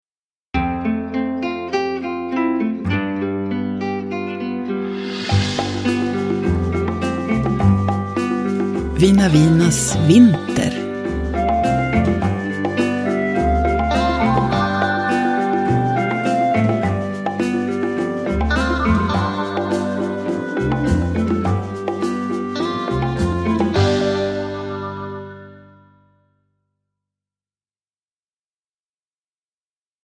Vina Vinas vinter – Ljudbok – Laddas ner
Uppläsare: Jujja Wieslander